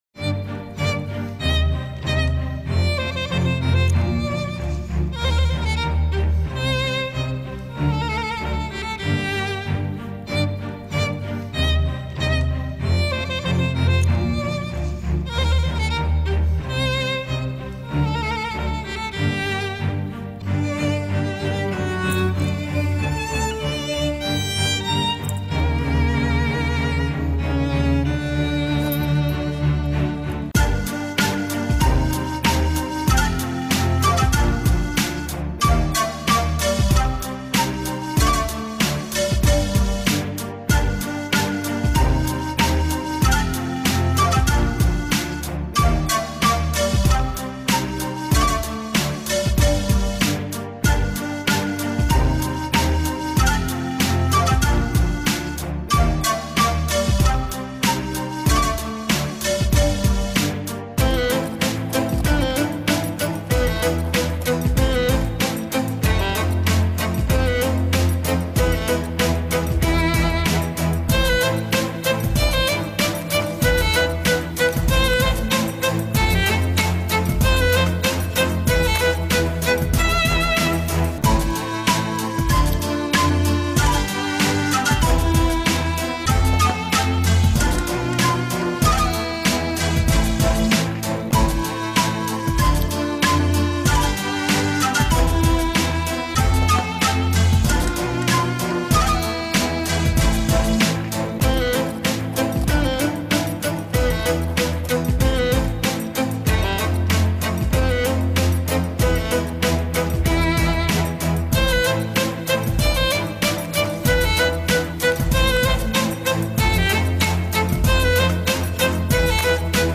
Mocart__Koncert_dlya_skripki_s_orkestrom_www_mixmuz_.mp3